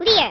File File history File usage Metadata Dev-SMW2-Ver0-sound-back_up-sdclear_sbn.ogg  (Ogg Vorbis sound file, length 0.3 s, 121 kbps) Summary This file is an audio rip from a(n) SNES game.